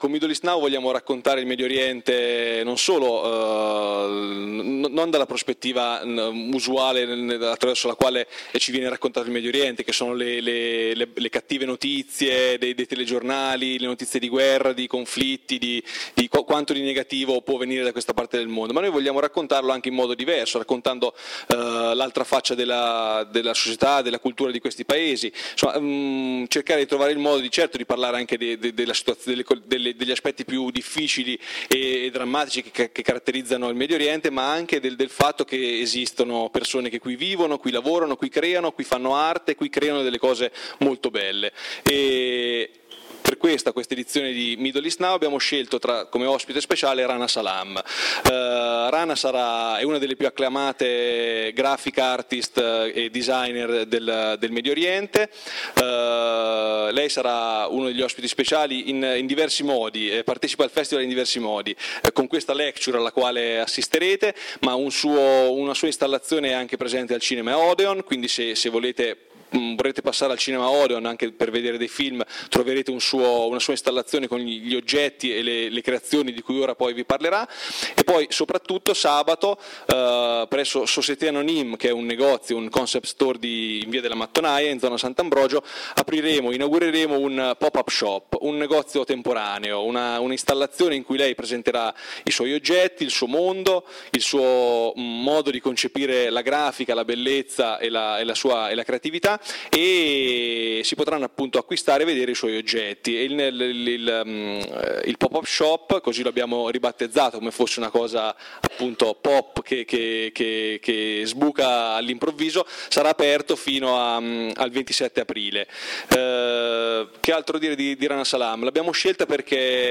Un’idea di bellezza nel Middle East pop design Evento speciale in collaborazione con Festival Middle East Now (Firenze, 3-8 aprile 2013)
Il talk si svolgerà in lingua inglese con traduzione in italiano.